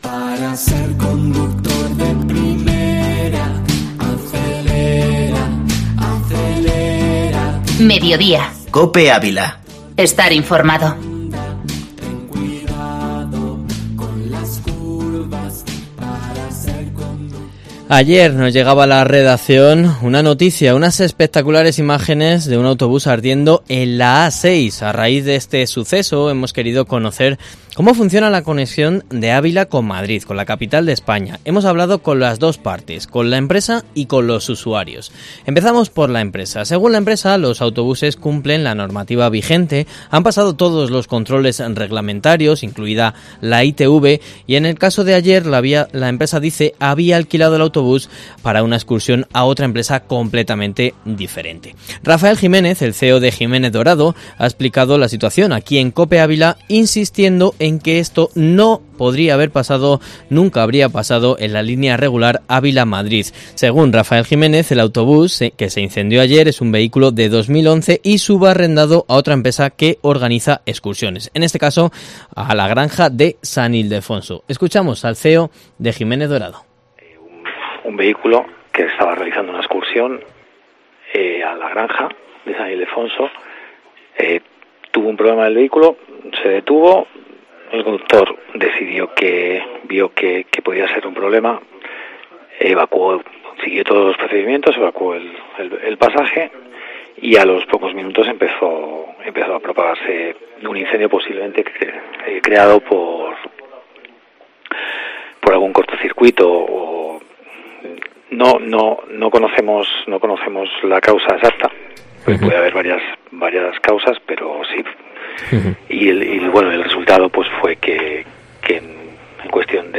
Reportaje Mediodia COPE Ávila